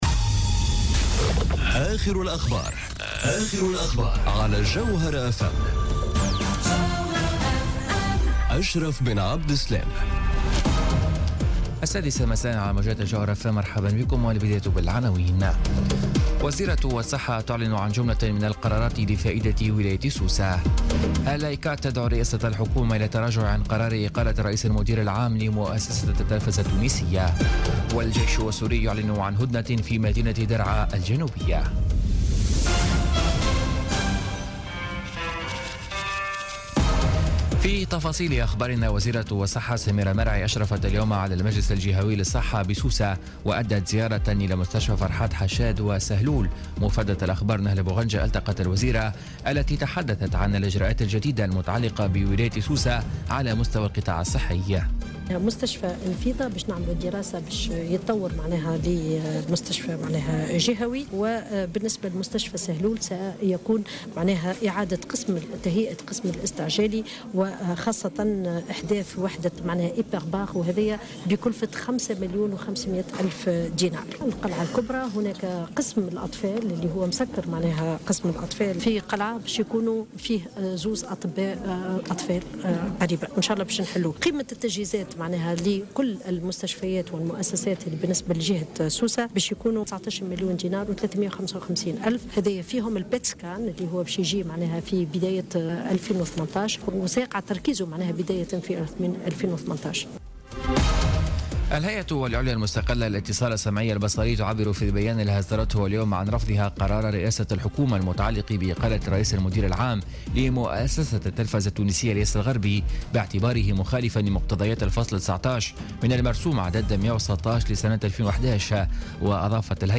نشرة أخبار السادسة مساء ليوم السبت 17 جوان 2017